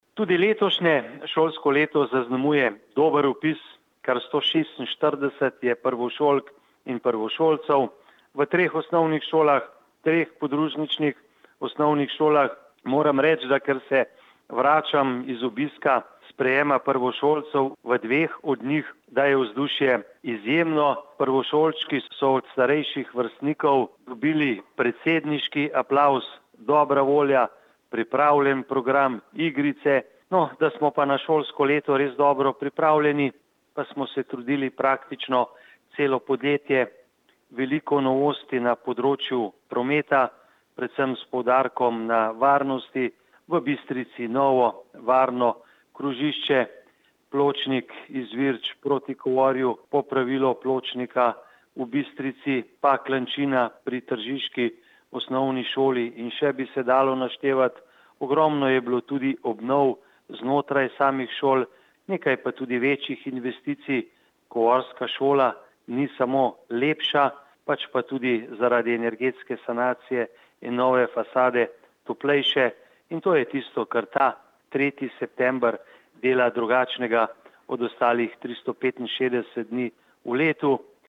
izjava_zupanobcinetrzicmag.borutsajovicoprvemsolskemdnevu.mp3 (2,0MB)